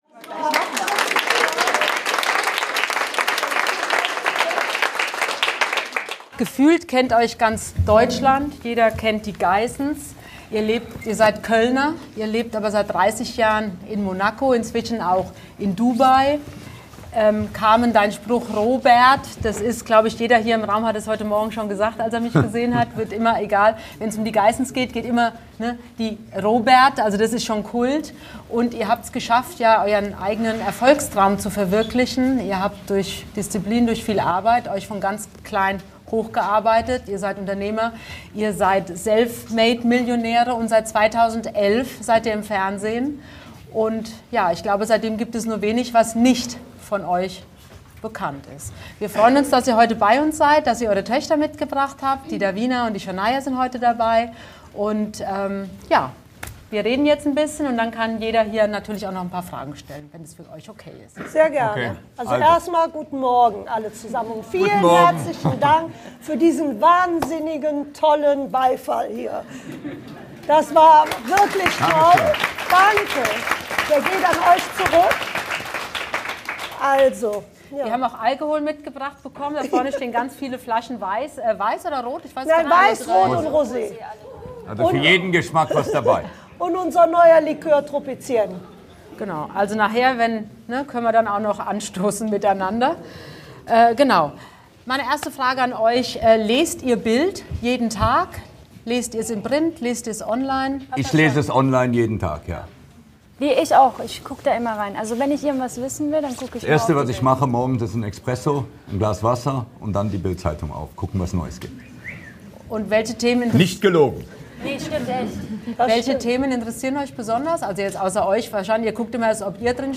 Die Geissens besuchen die BILD-Redaktion in Berlin. Beim munteren Talk geht es um Kleiderschränke, Abnehmen und das Leben zwischen Ruhm und familiärer Normalität.